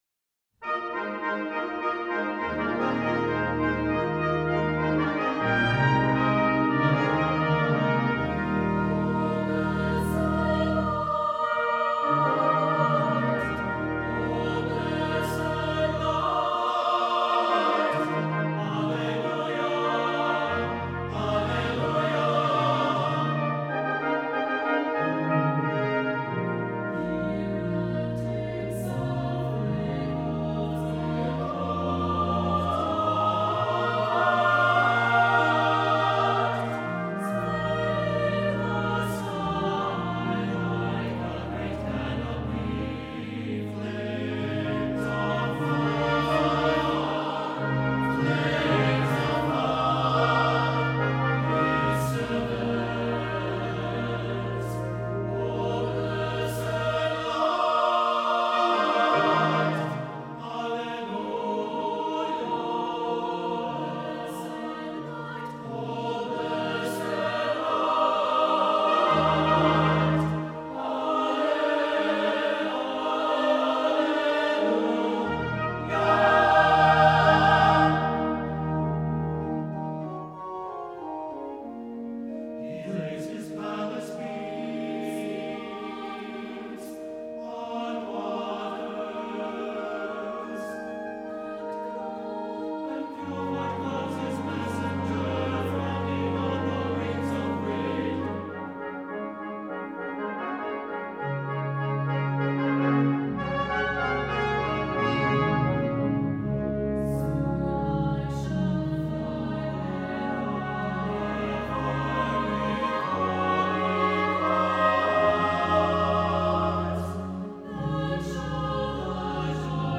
Voicing: SSATB and Organ